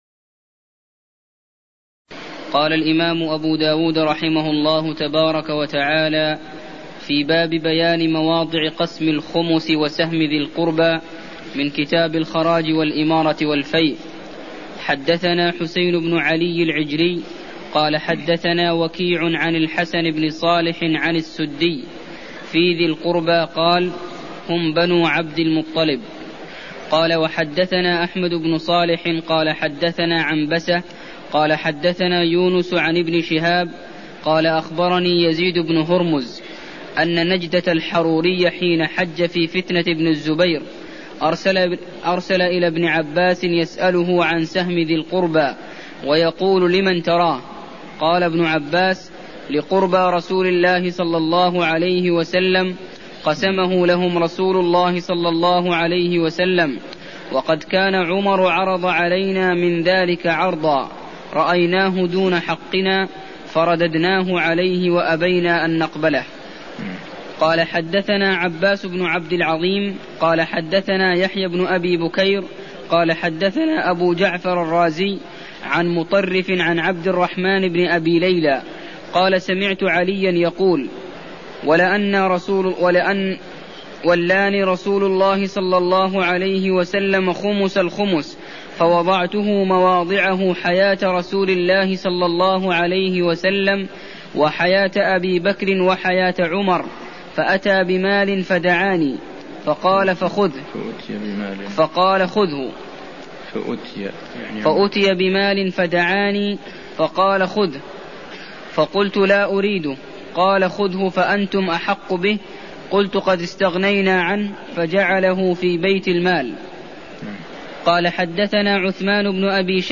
المكان: المسجد النبوي الشيخ: عبدالله الغنيمان عبدالله الغنيمان من باب ما جاء في البيعة إلى باب تدوين العطاء (04)